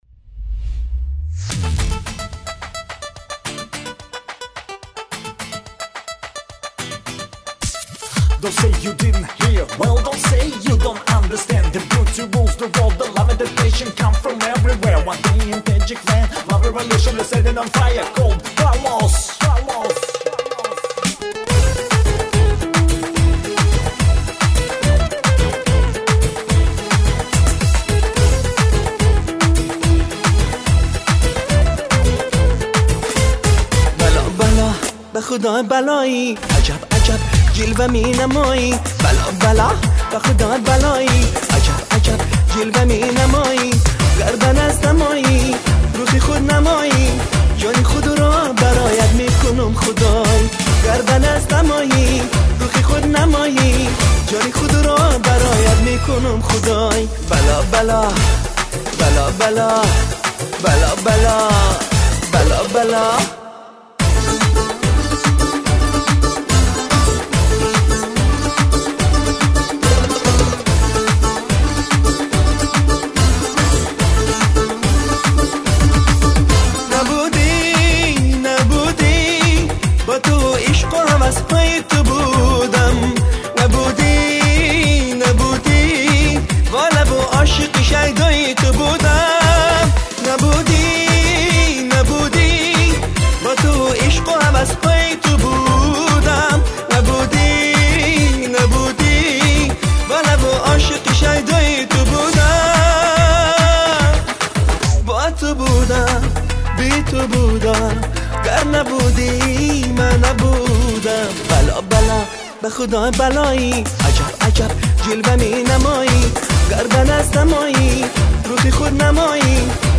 Главная » Файлы » Каталог Таджикских МР3 » Эстрада